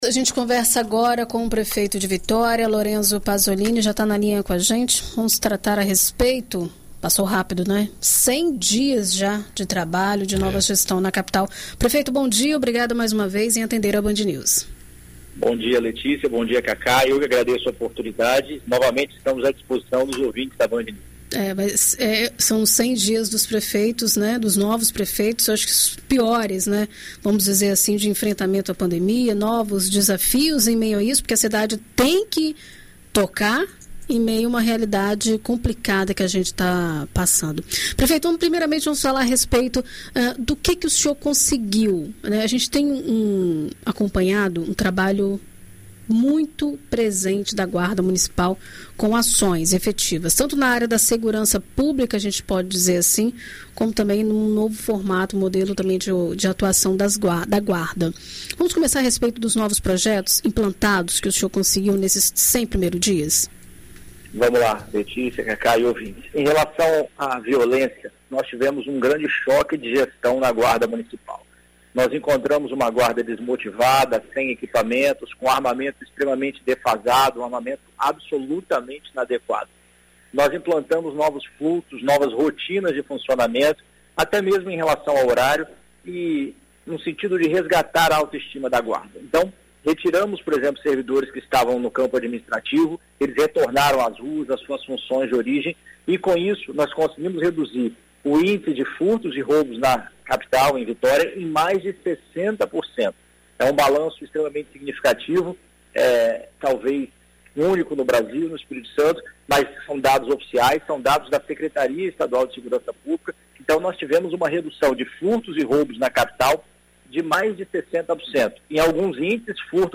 Em entrevista à Rádio BandNews FM Espírito Santo, o prefeito de Vitória destacou as principais ações implantadas no primeiros 100 dias de mandato